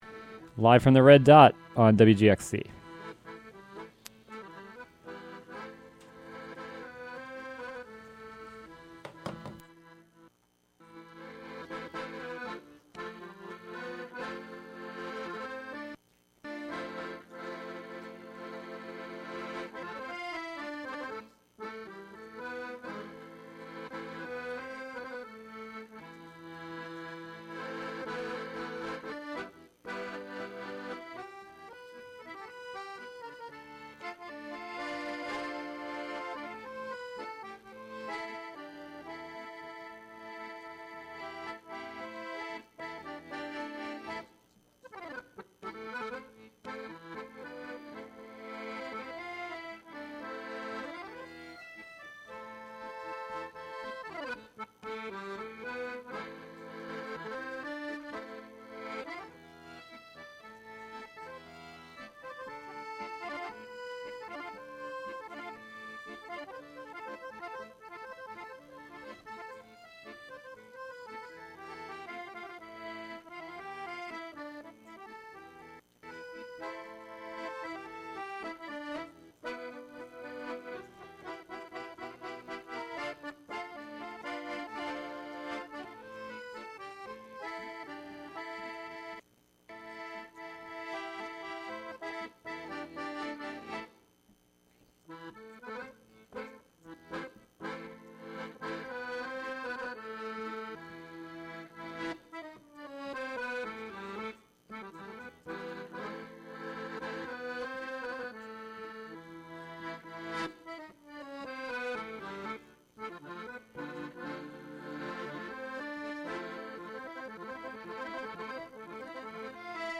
Live from The Red Dot Open Mic